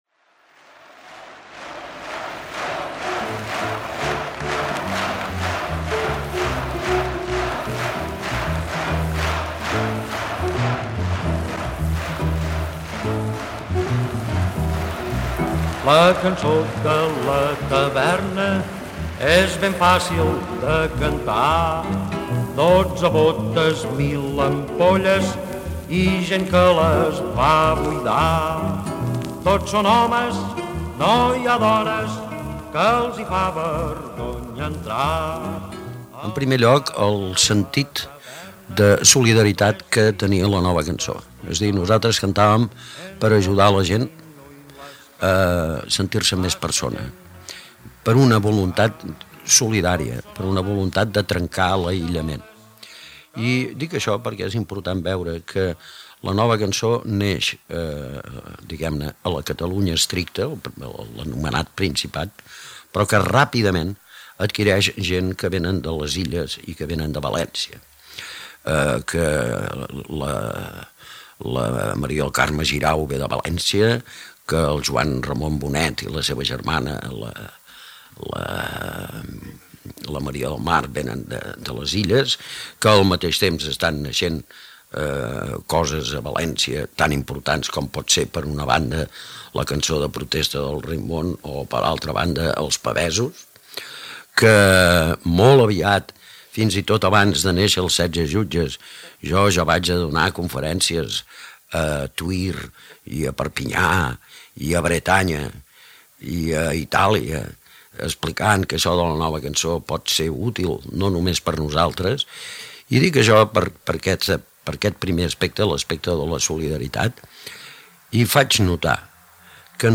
Declarció de Miquel Porter Moix
Gènere radiofònic Musical